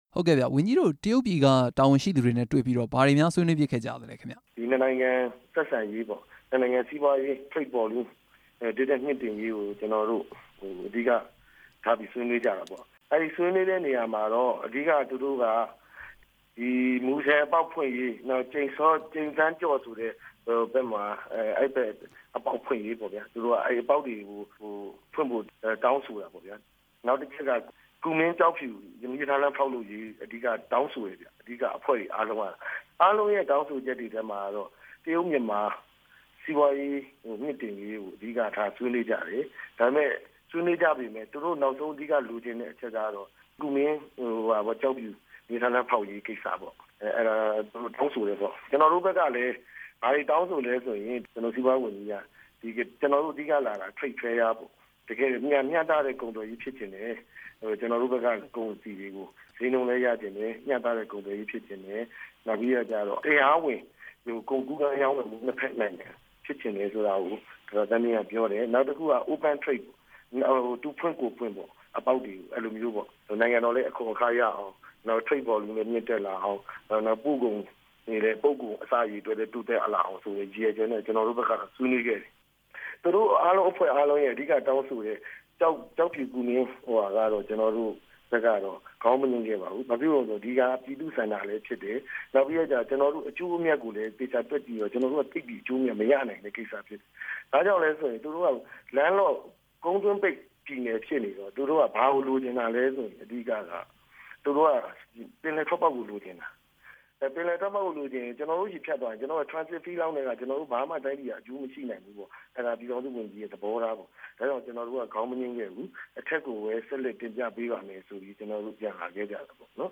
ရှမ်းပြည်နယ် စီမံကိန်းနဲ့ စီးပွားရေးဝန်ကြီး ဦးစိုးညွန့်လွင် နဲ့ မေးမြန်းချက်